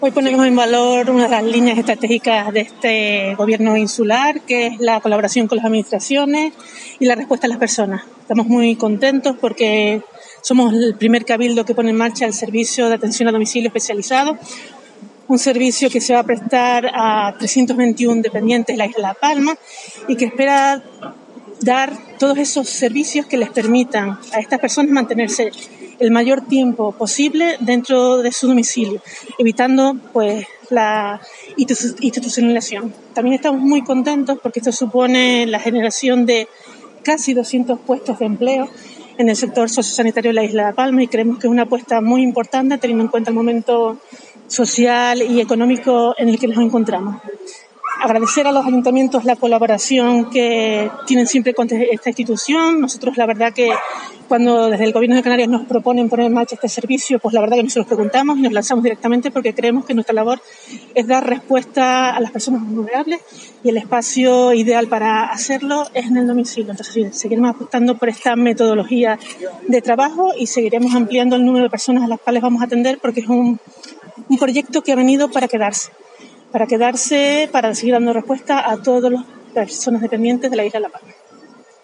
Consejera de Acción Social Cab La Palma, Nieves Hernández.mp3